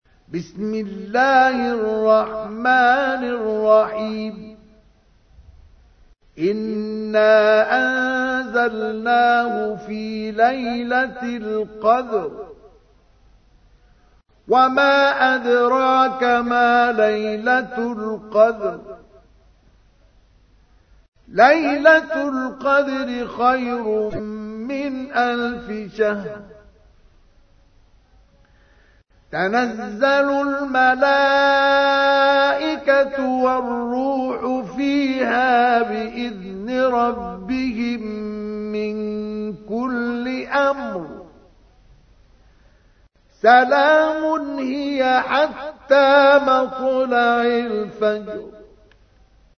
تحميل : 97. سورة القدر / القارئ مصطفى اسماعيل / القرآن الكريم / موقع يا حسين